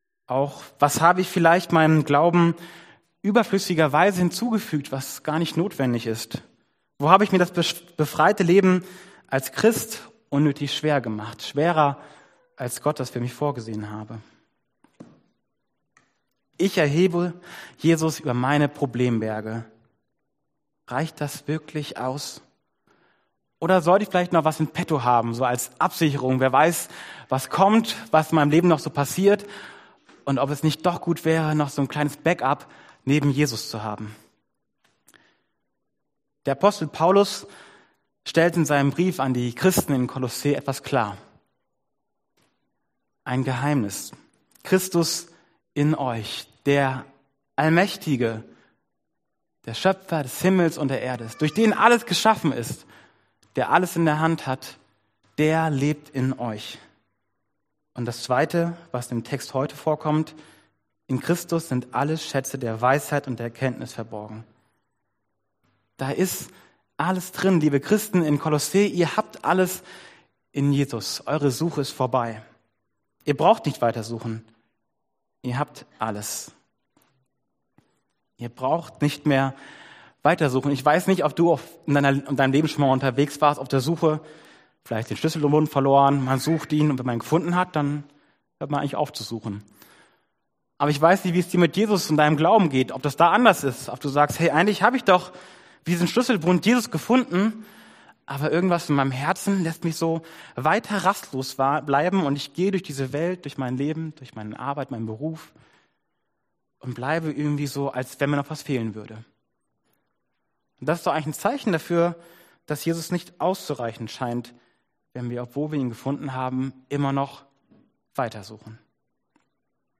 Kolosser 2,1-23 Dienstart: Predigt « Christus in mir Kleiner Ratgeber für ein gutes Leben